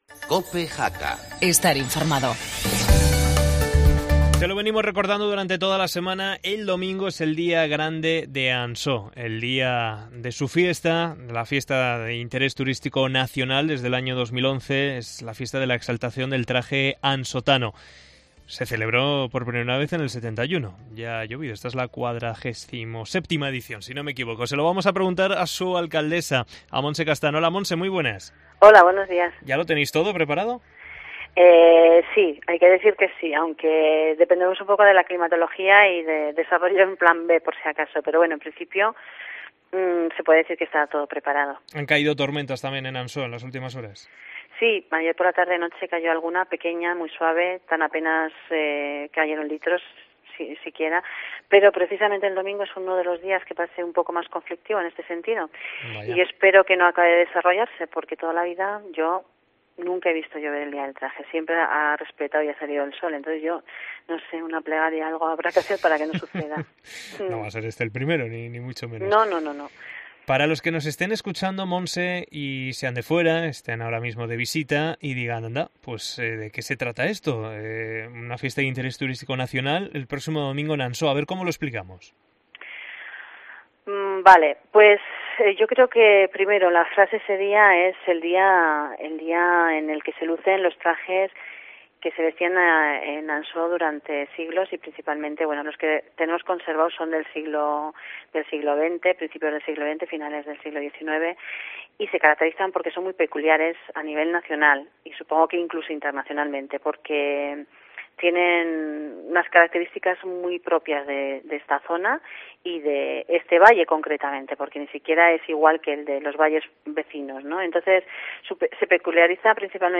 Entrevista a la alcaldesa de Ansó